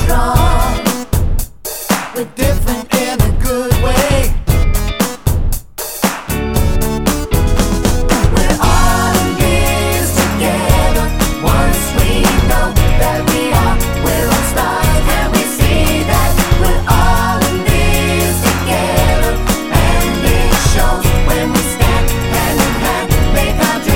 No Backing Vocals Soundtracks 3:51 Buy £1.50